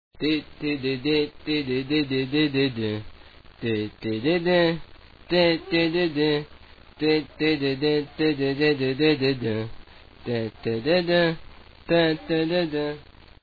sorry about the voice..